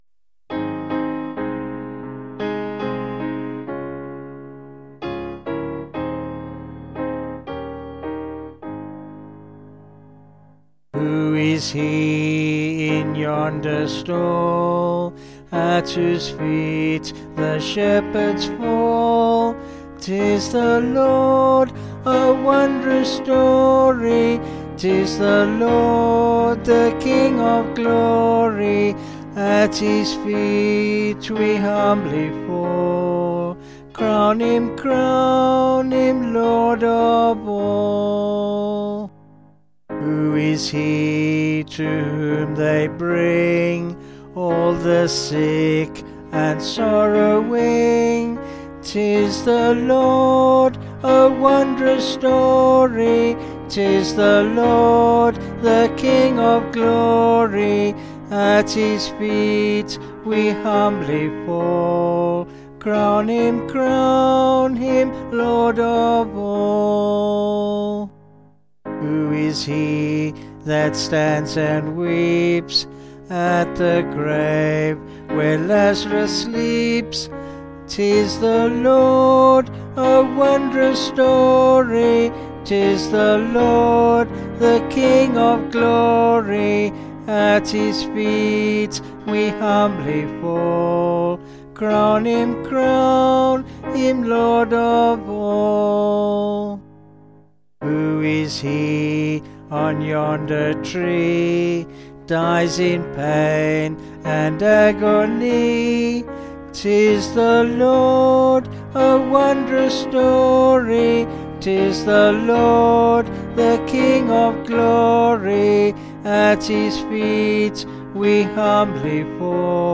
Vocals and Piano   264kb Sung Lyrics